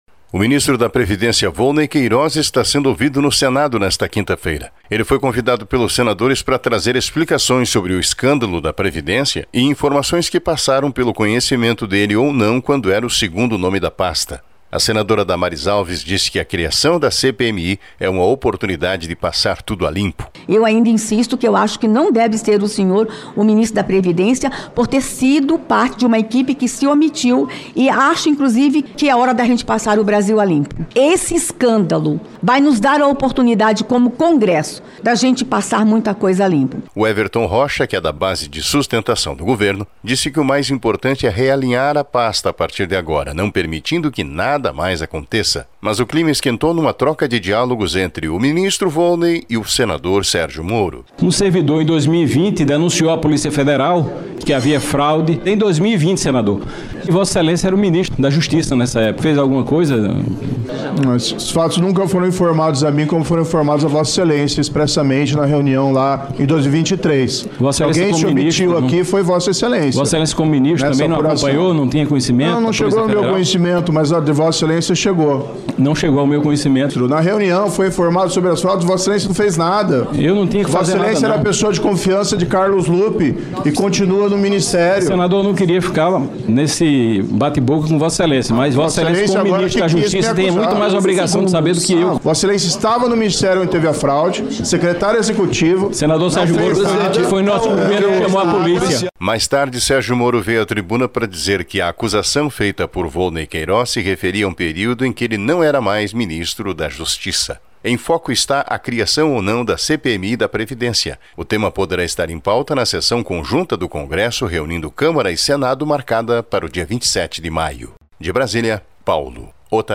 Durante audiência no Senado Ministro da Previdência e Senador Sérgio Moro batem boca